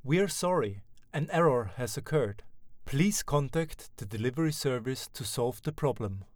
error-en.wav